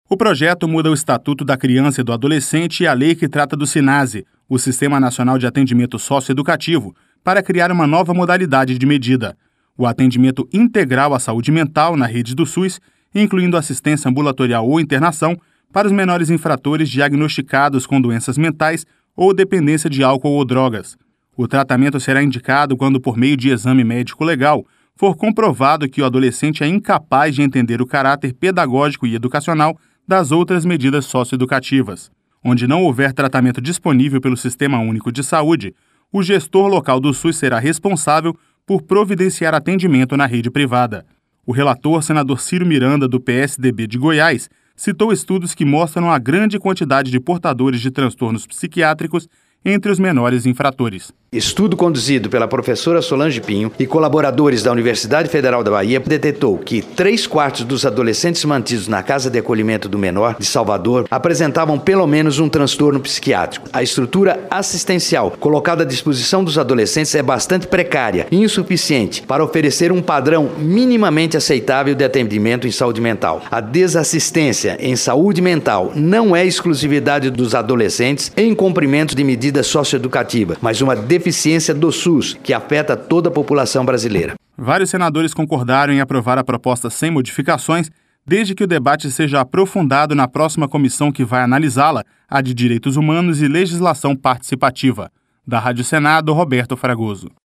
O relator, senador Cyro Miranda, do PSDB de Goiás, citou estudos que mostram a grande quantidade de portadores de transtornos psiquiátricos entre os menores infratores.